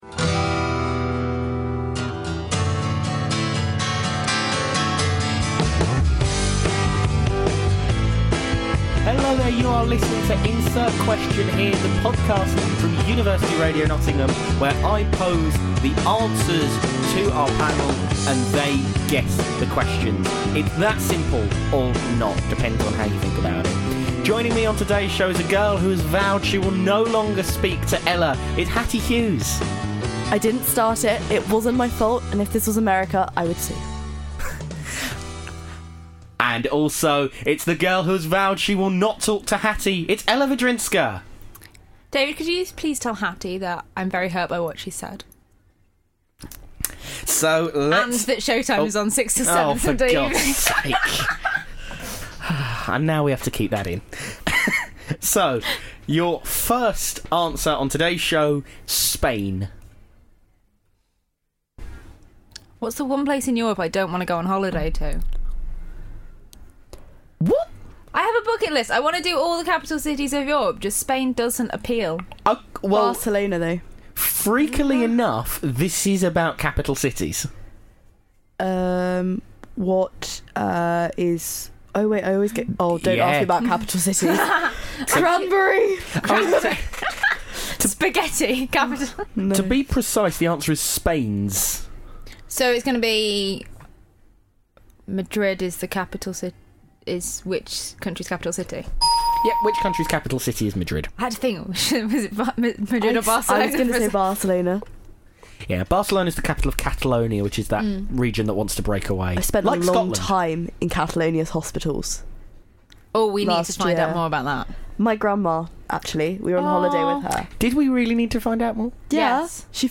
3 students. 1 pack of children's Trivial Pursuit cards.